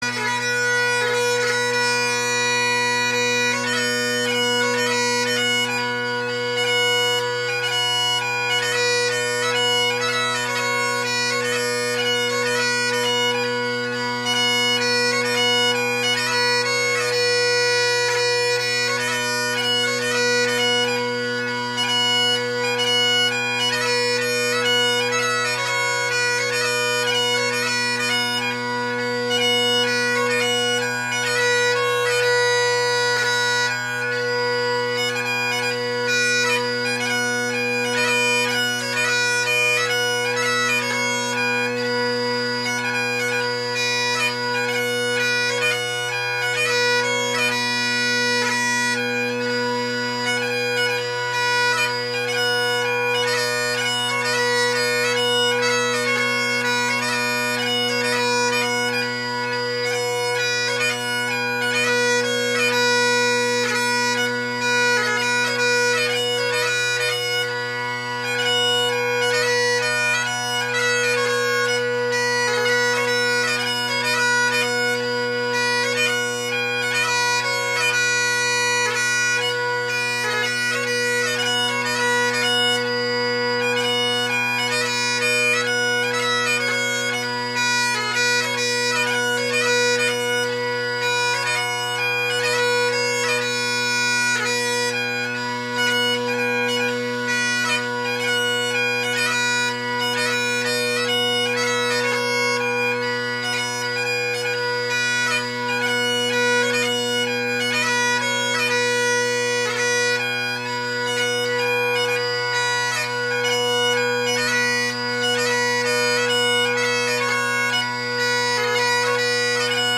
Bagpipe Sound Research
played it kinda slow